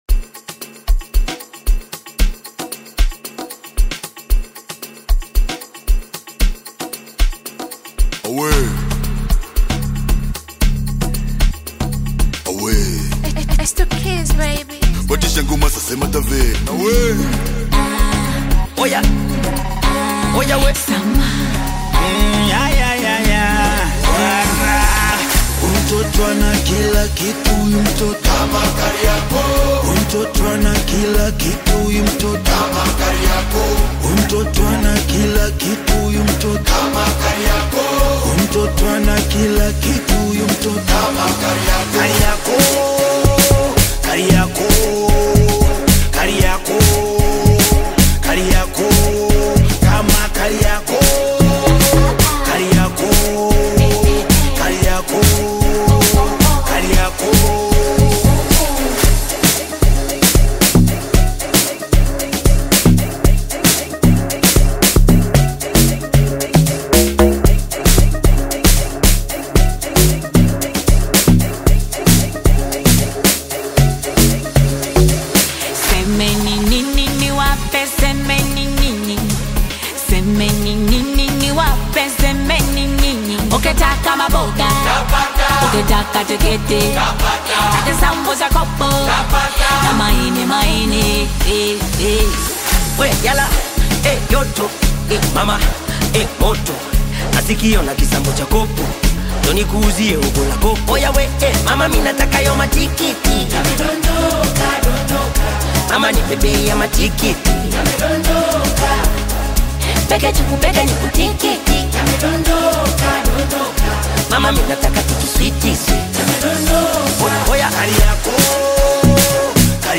Bongo Flava Amapiano, Club Banger music